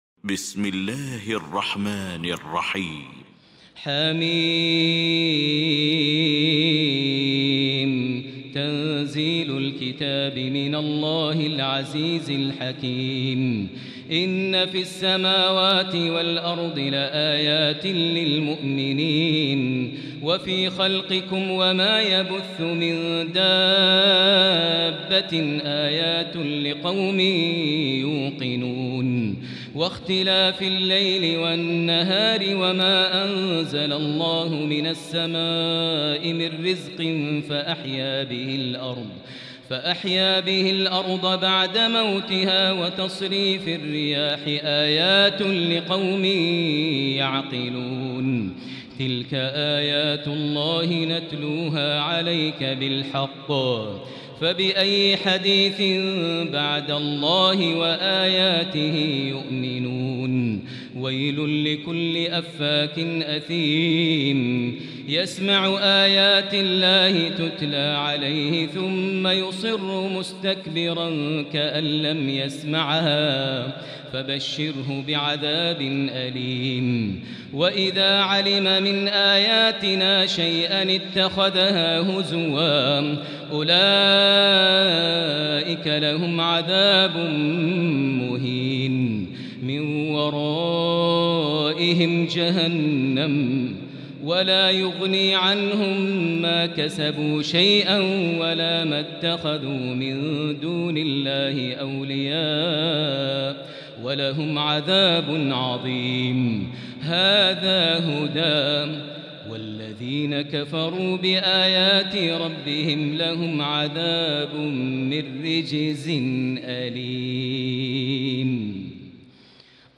المكان: المسجد الحرام الشيخ: فضيلة الشيخ ماهر المعيقلي فضيلة الشيخ ماهر المعيقلي الجاثية